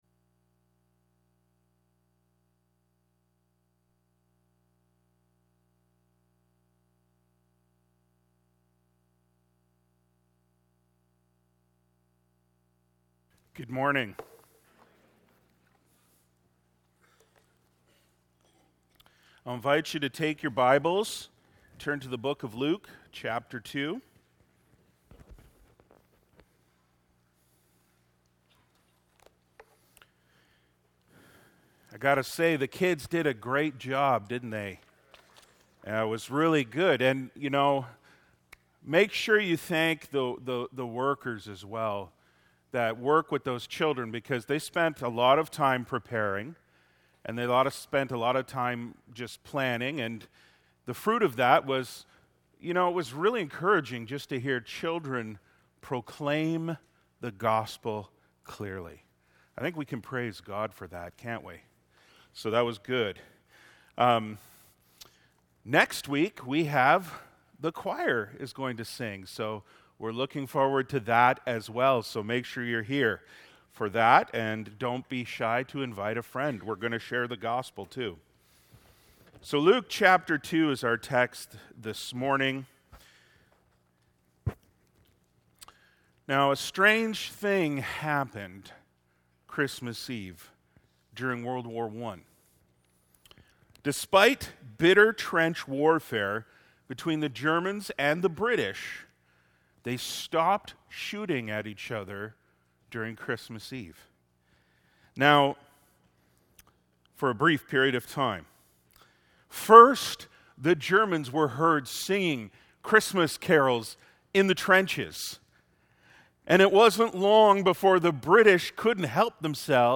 Sermons | Faith Baptist Church